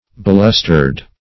Balustered \Bal"us*tered\ (-t[~e]rd)